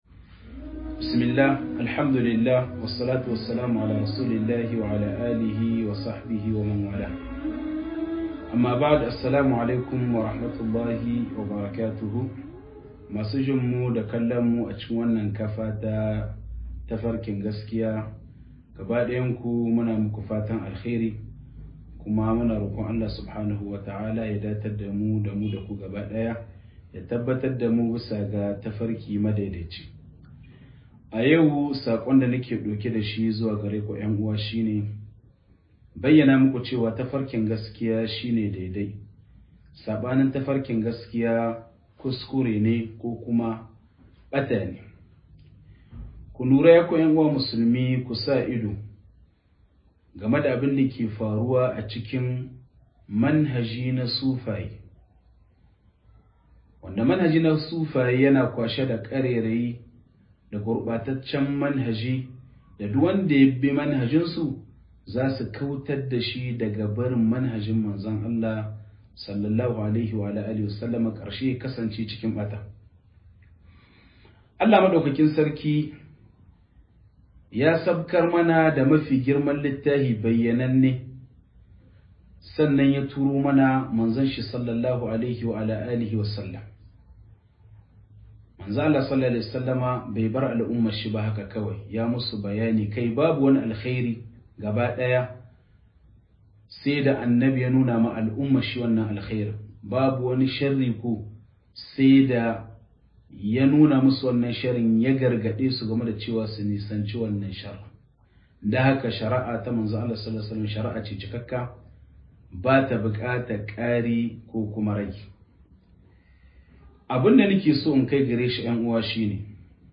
Raddi_ga_mabiya_akidun_sufaye(360p)~1 - MUHADARA